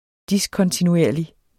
Udtale [ ˈdiskʌntinuˌeɐ̯ˀli ]